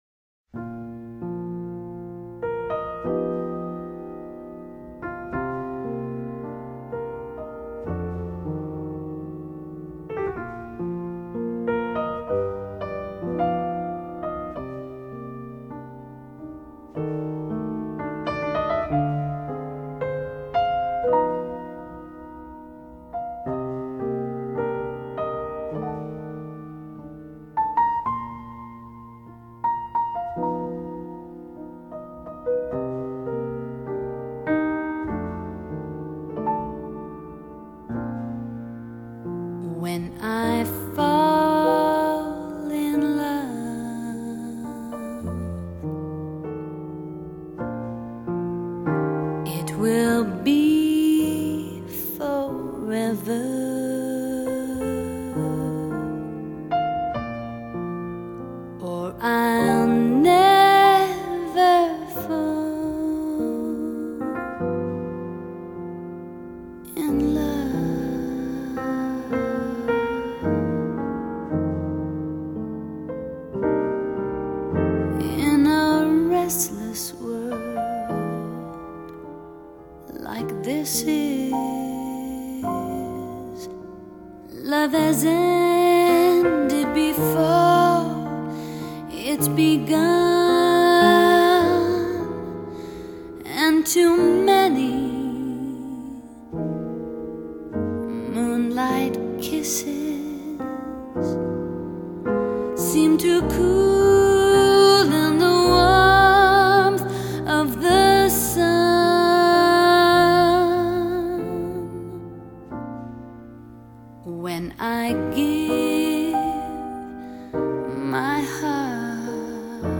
勾人心魂的細膩歌聲 墜入爵士流行的無限遐思；帶點慵懶、加點灑脫、和著些許放肆、煽情，再來點幻想…
擅於抒發都會男女情愫的性感女聲